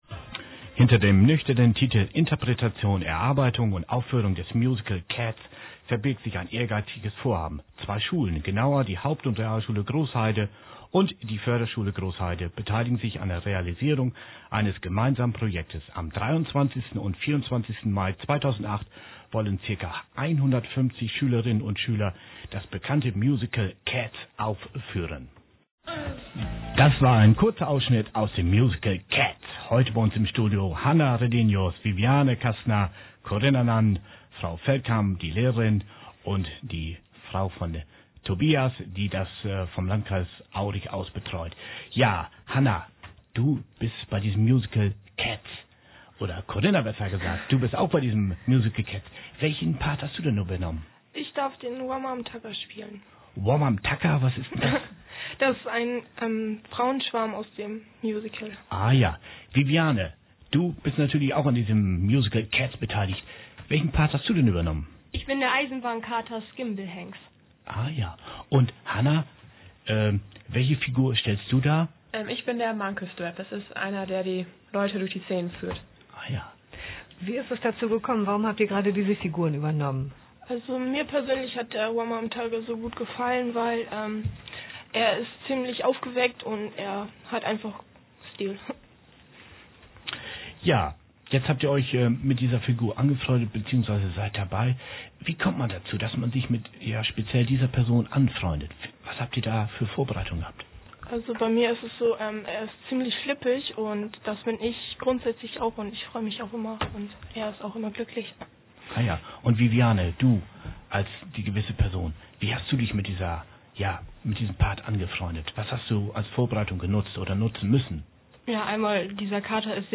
Interview als mp3leicht gek�rztmit freundlicher Genehmigung von Radio Ostfriesland